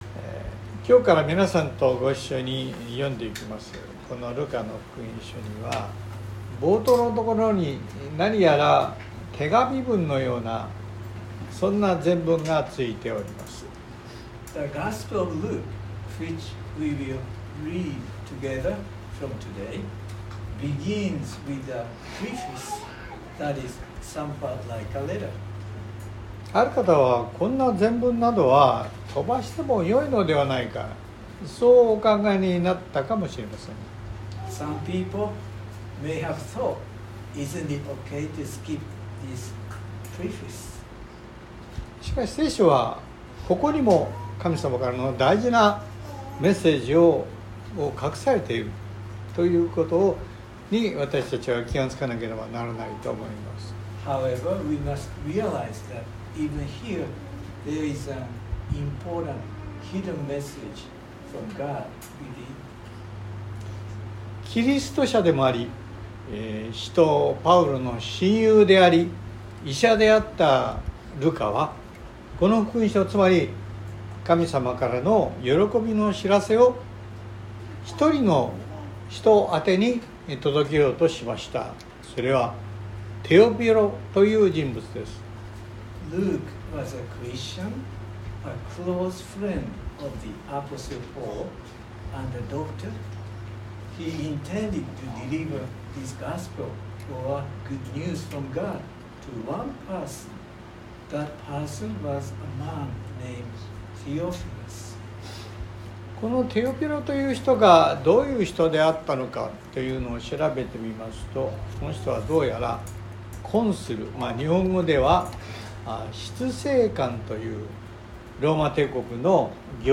（日曜礼拝録音）【iPhoneで聞けない方はiOSのアップデートをして下さい】➀きょうから皆さんと一緒に読んでゆきますこのルカの福音書には、冒頭のところに何やら手紙文のような前文がついております。